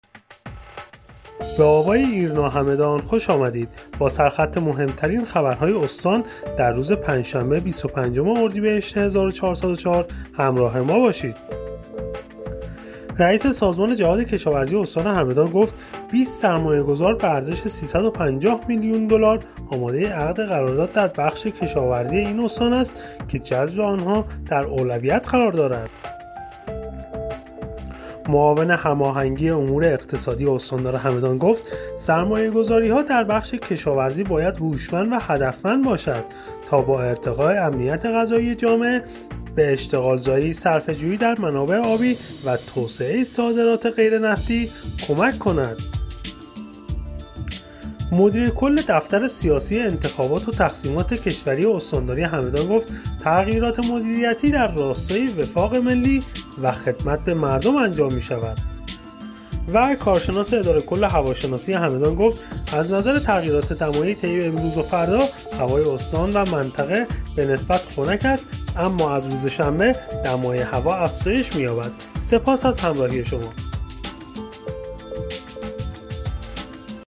همدان-ایرنا- مهم‌ترین عناوین خبری دیار هگمتانه را هر شب از بسته خبر صوتی آوای ایرنا همدان دنبال کنید.